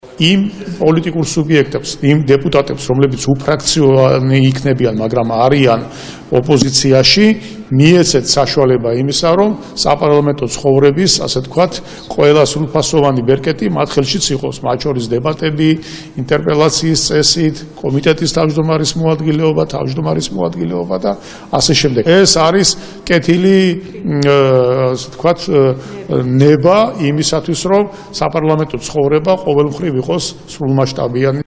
მოისმინეთ ირაკლი ქადაგიშვილის კომენტარი